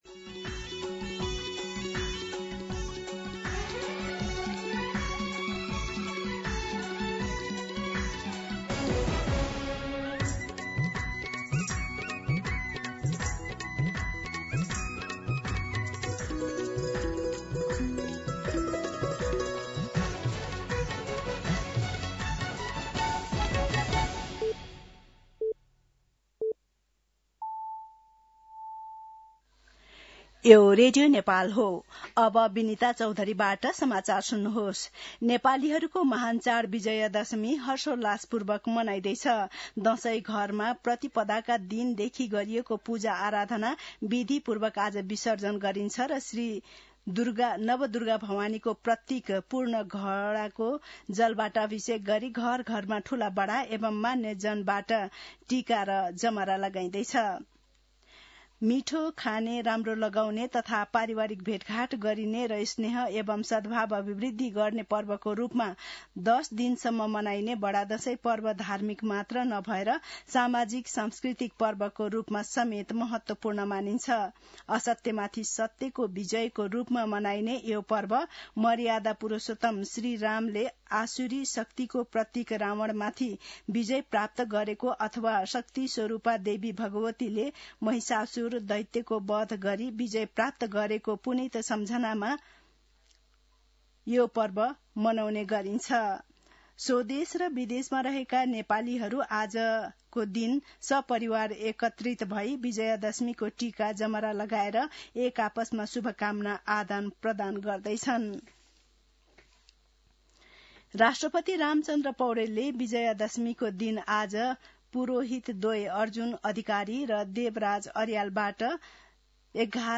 दिउँसो ४ बजेको नेपाली समाचार : १६ असोज , २०८२
4-pm-Nepali-News.mp3